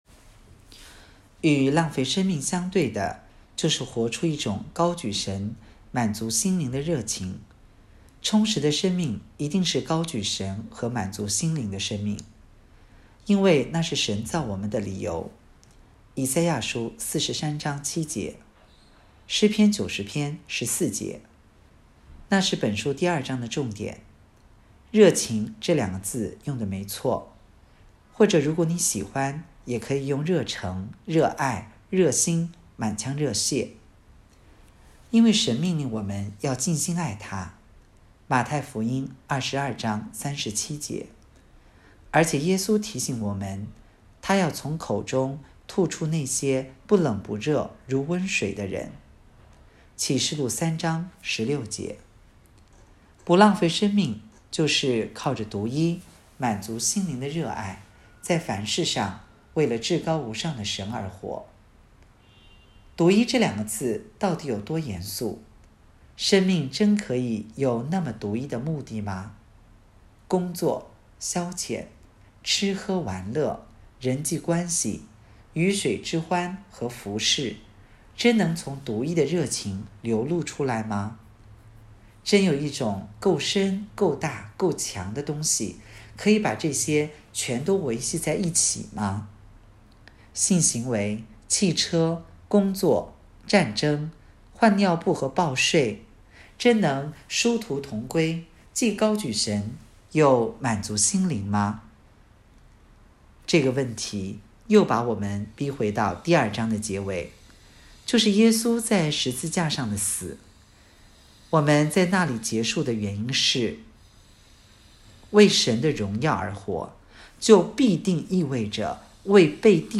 2023年8月10日 “伴你读书”，正在为您朗读：《活出热情》 https